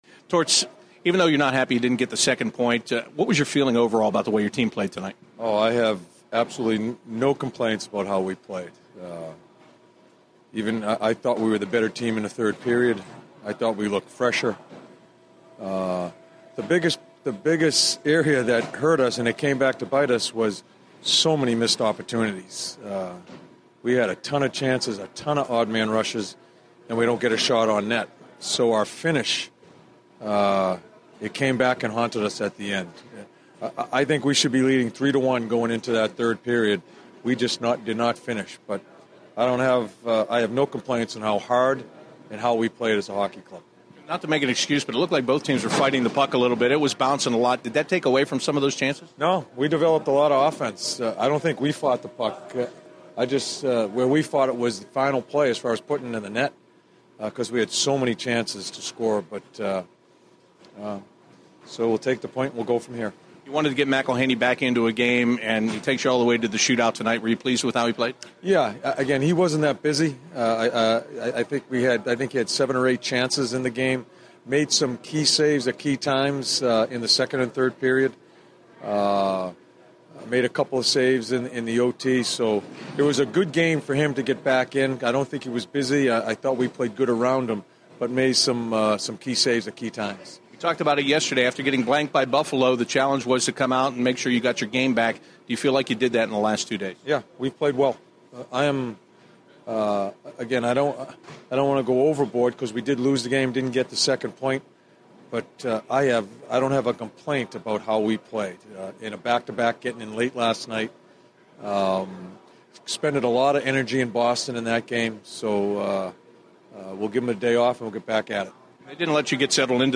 John Tortorella Post-Game 02/23/16